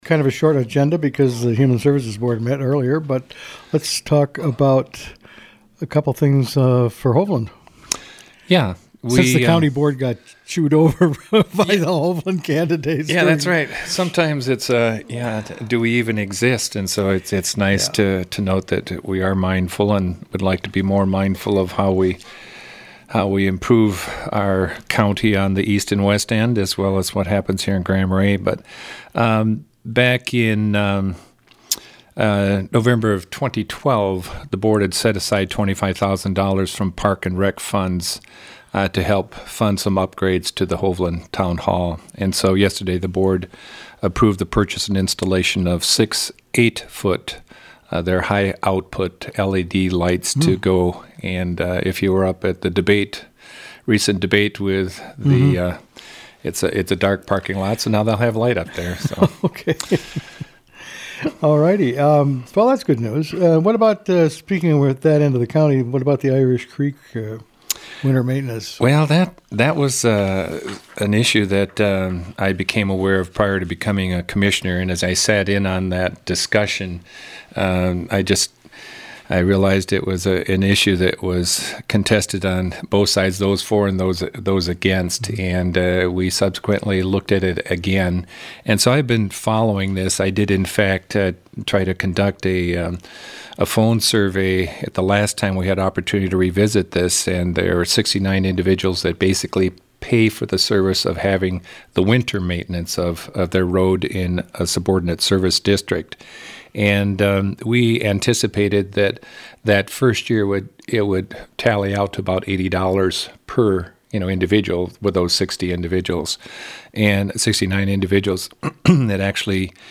spoke with Commissioner Garry Gamble about plowing and fixing the YMCA locker room floor.